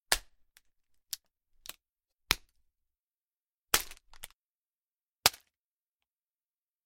Звуки шоколада
Звук хруста ломающейся шоколадной плитки